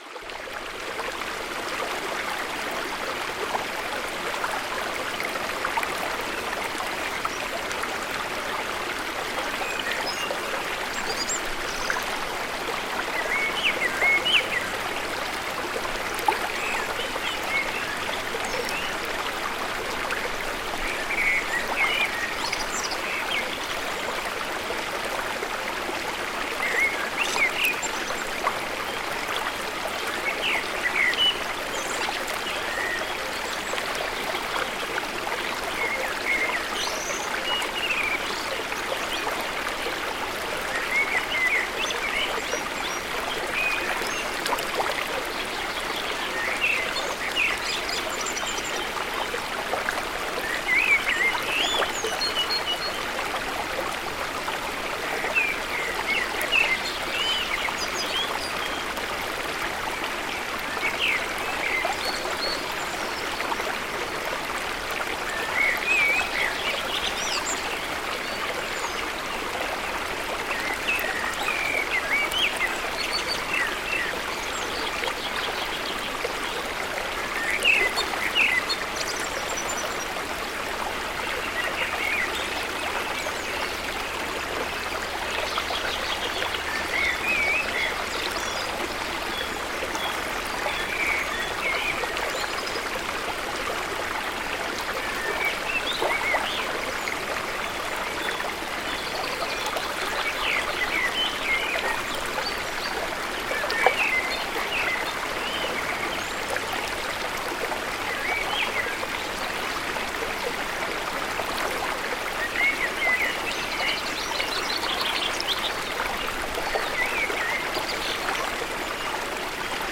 SOFORT-ENTSPANNUNG: Bergfluss-Kraft mit ungezähmter Natur
Naturgeräusche